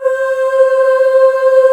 F.CHORUS C4.wav